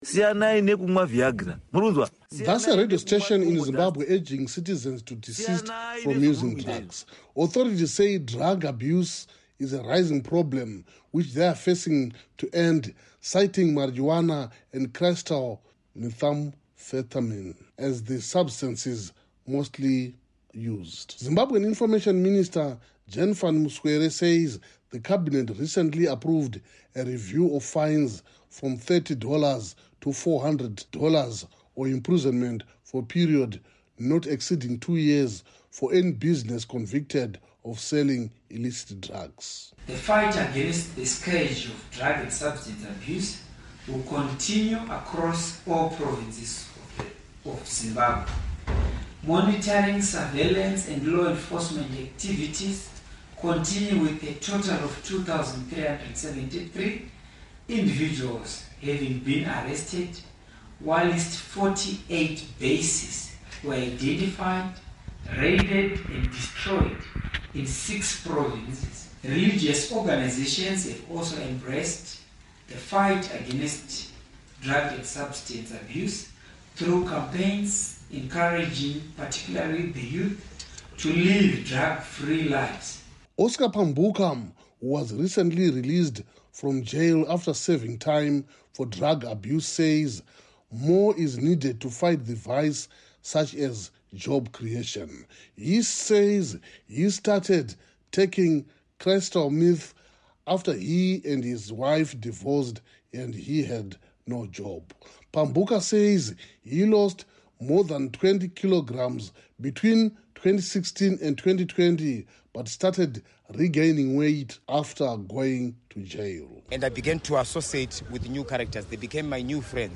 reports from Zimbabwe's capital, Harare